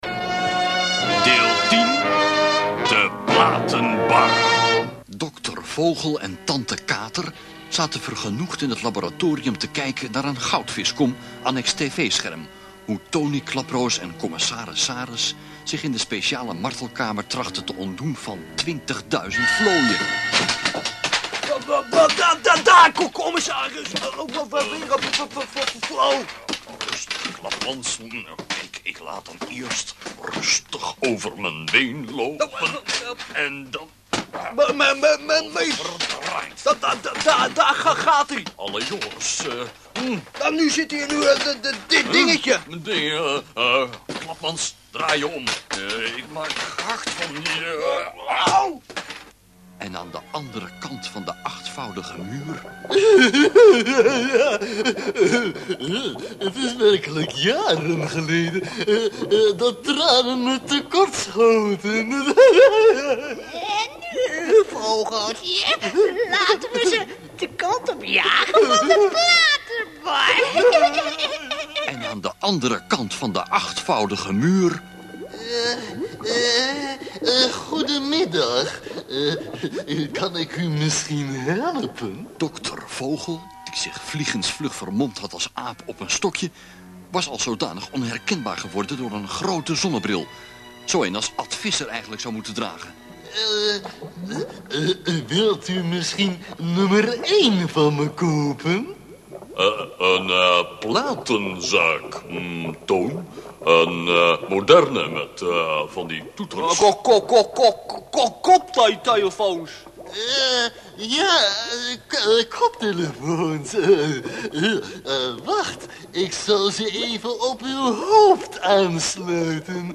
Ik vond in een oude doos een paar tapes met mono opnames van crappy kwaliteit.